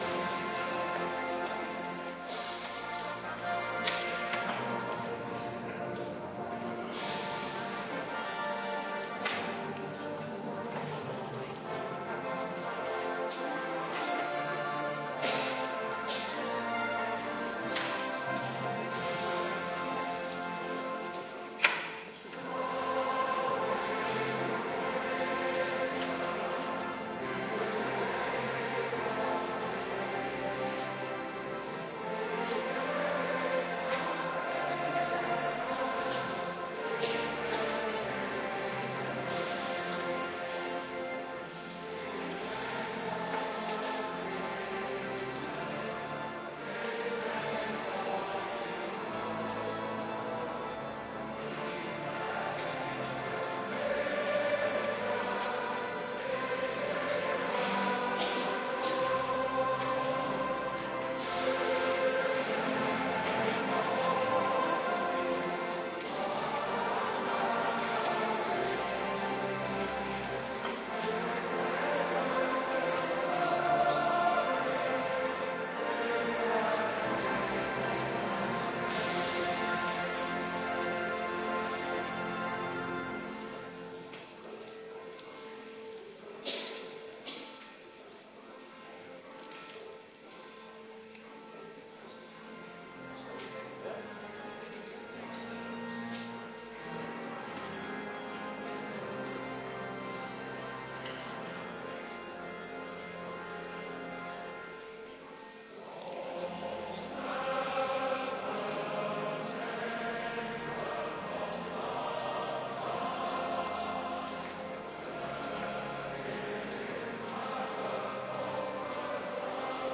Kerkdiensten
Adventkerk Zondag week 7